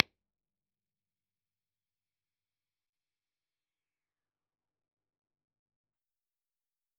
valhallir-deconvolver - This is the main deconvolving tool for Valhallir IRs.
ir.wav